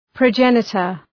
Προφορά
{prəʋ’dʒenıtər} (Ουσιαστικό) ● πρόγονος